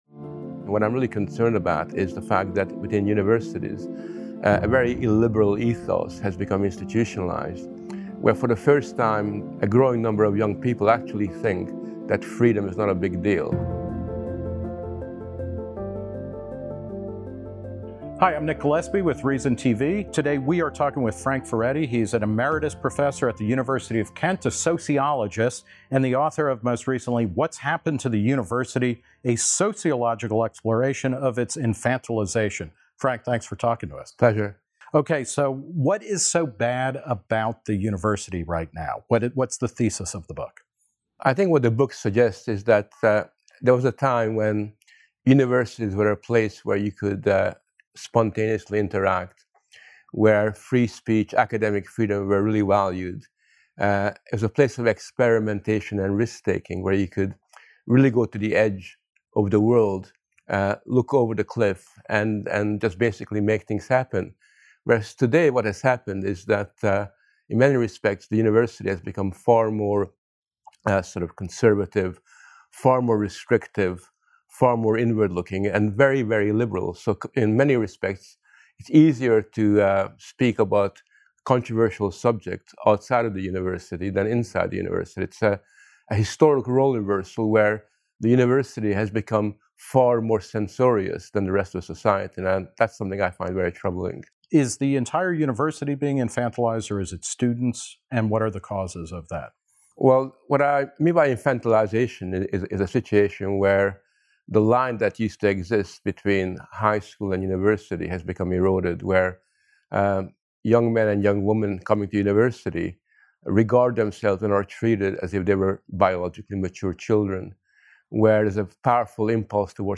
Furedi sat down with Reason's Nick Gillespie to talk about the roots of this intellectual shift on campus—and how to fix it.